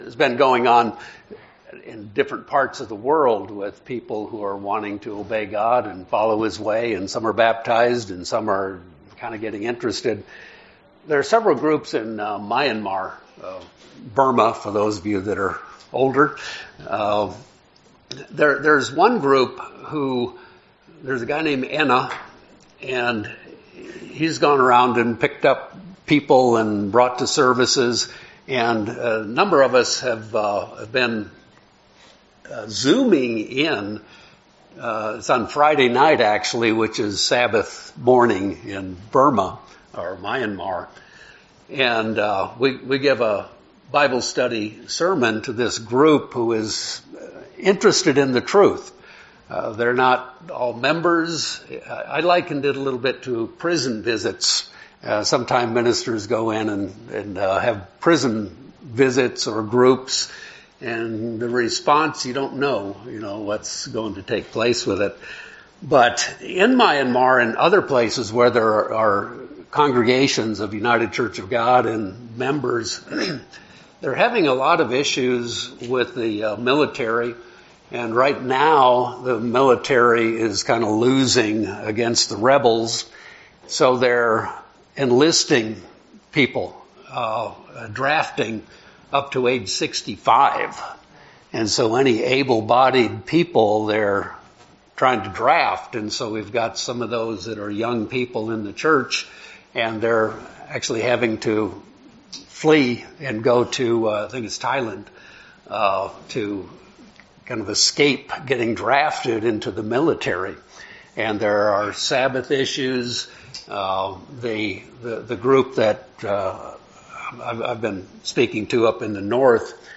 We continue to explore the good, bad, and ugly aspects of Human nature. We then see that by understanding the Laws of Human Nature, we can use God's spirit to become more like Christ. This sermon is part 2 in a 3 part sermon series.